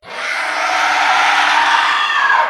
falling_skull1.ogg